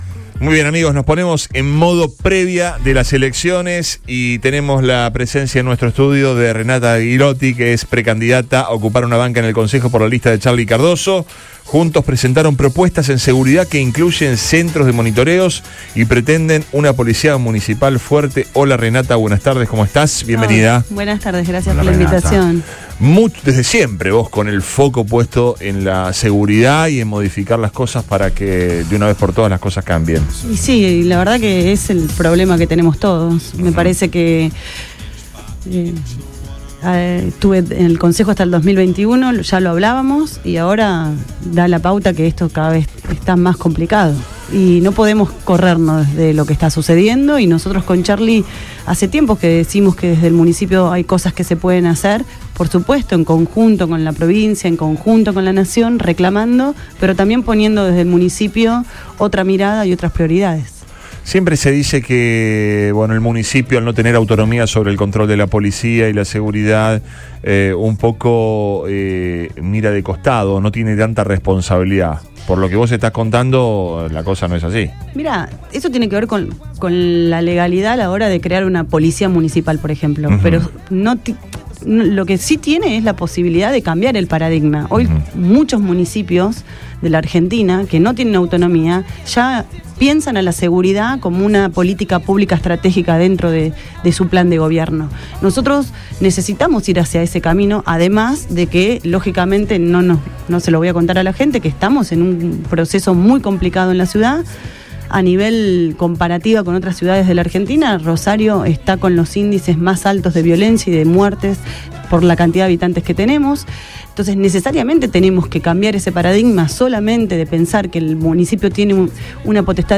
estuvo al aire en Después de Todo , hablando de seguridad, el Concejo y sus proyectos en caso de asumir.